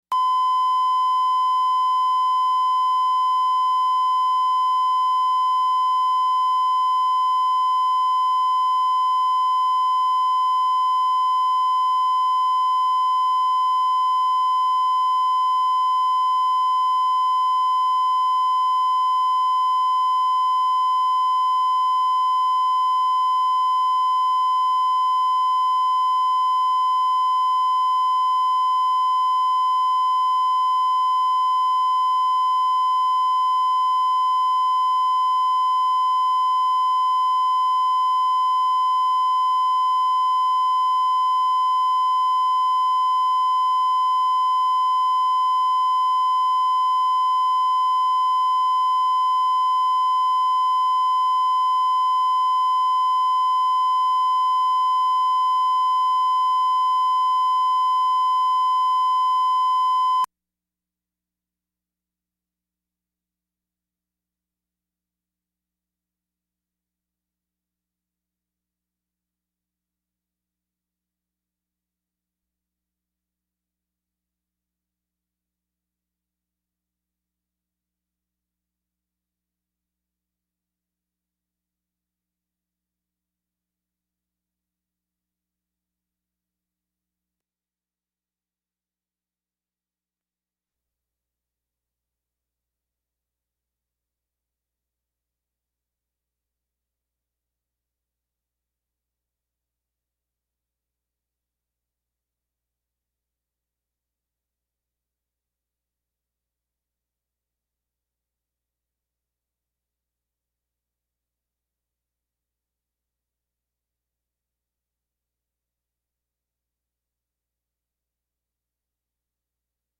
Terry Gross is the host and an executive producer of Fresh Air, the daily program of interviews and reviews.